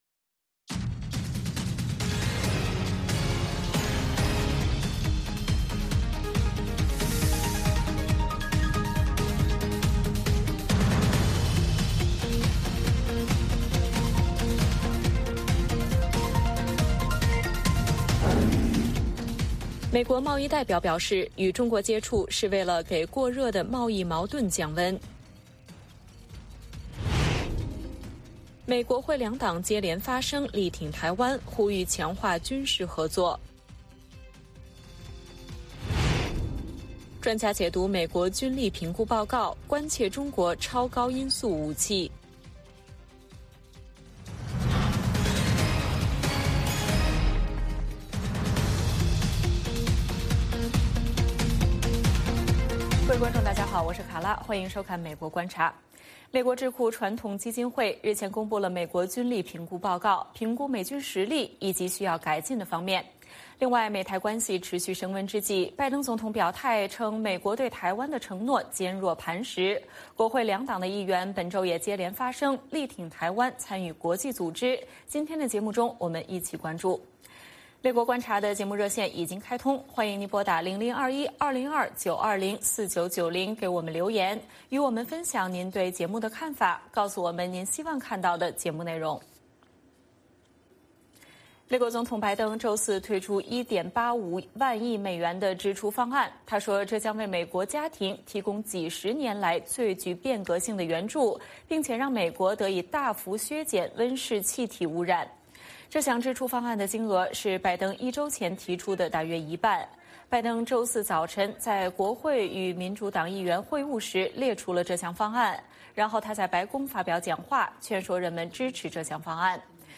北京时间早上6点广播节目，电视、广播同步播出VOA卫视美国观察。
节目邀请重量级嘉宾参与讨论。